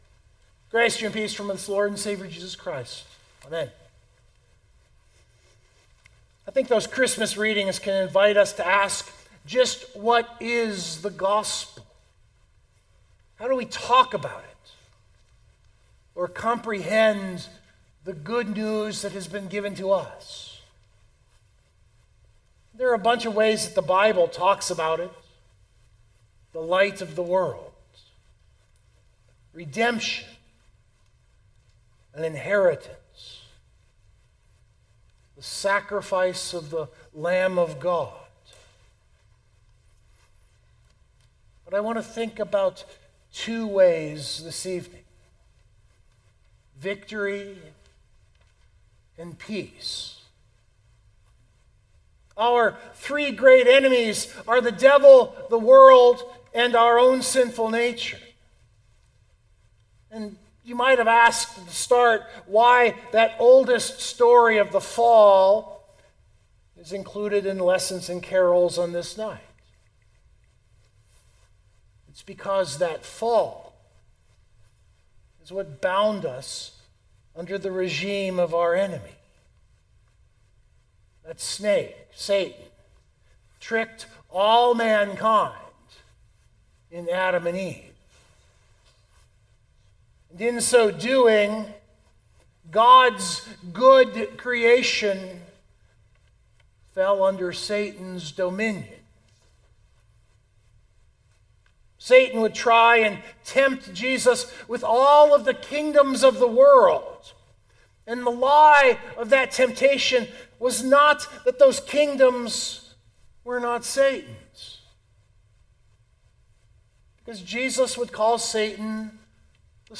The service was lessons and carols – a very traditional Christmas Eve service. When I was pondering what to preach this year the thing that struck my mind was how much these texts talked about the reign of God.